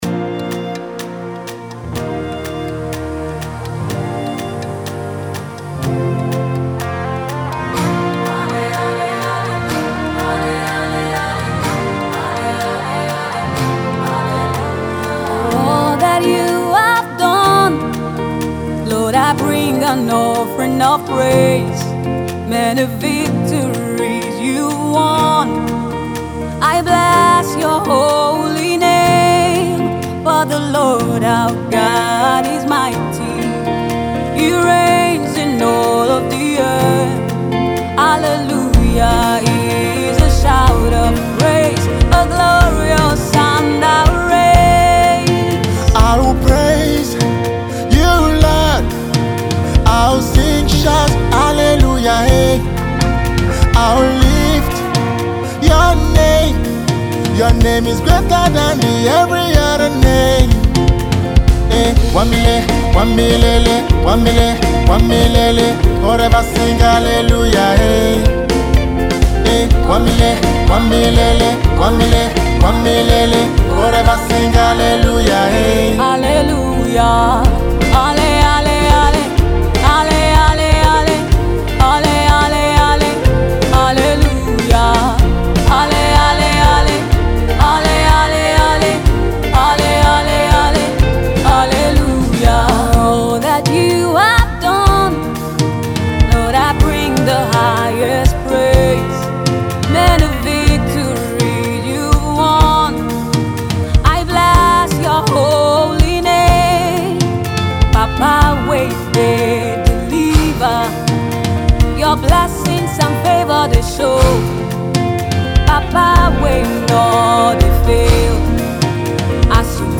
Emerging Gospel music minister
song of gratitude and praise